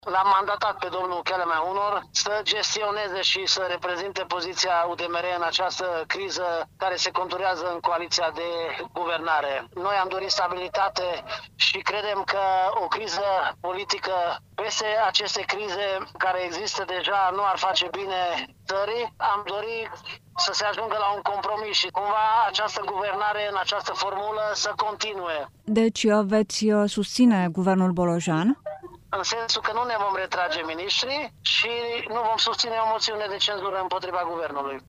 Purtătorul de cuvânt al UDMR, deputatul Csoma Botond: „Am dori să se ajungă la un compromis și această guvernare, în această formulă, să continue”